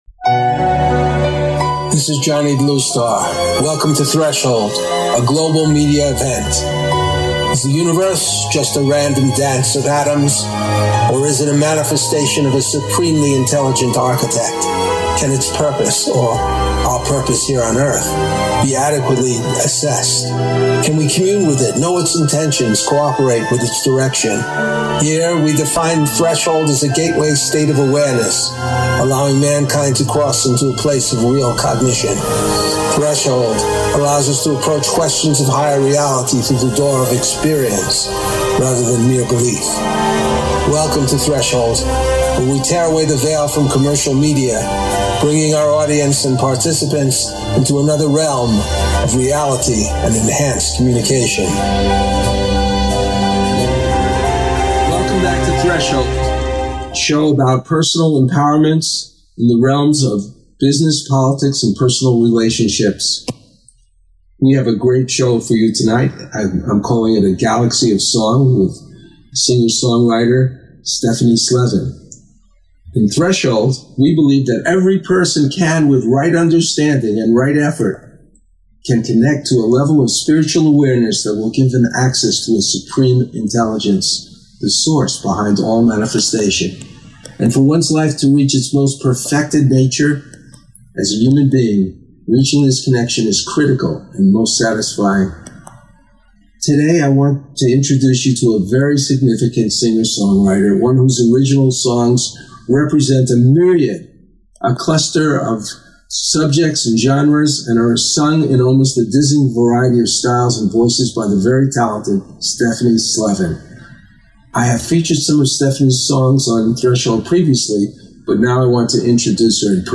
Talk Show Episode
If you listen to the songs in this week’s program, you will hear a profound difference in the way she sings each song, her voice changing in accent, in style and in delivery according to the spirit of each piece.